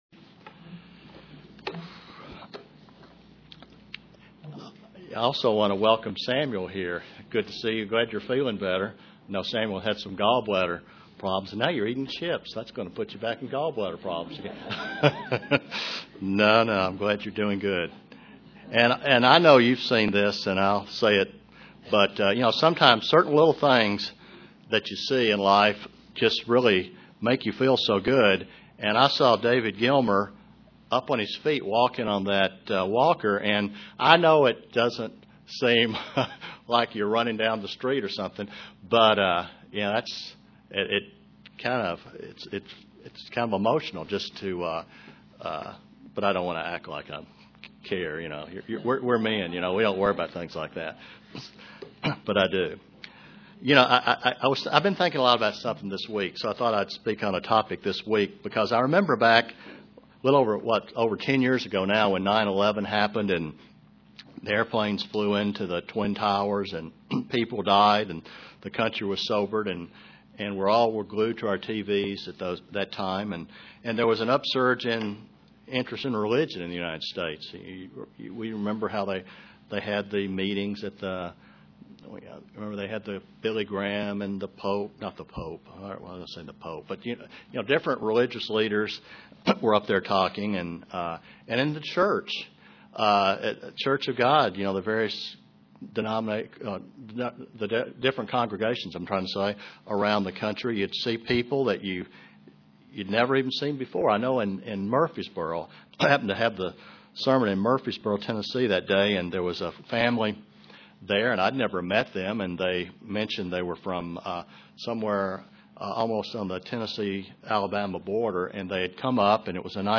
Given in Kingsport, TN
Print The importance of not betraying what we know to be true and not searing our conscience UCG Sermon Studying the bible?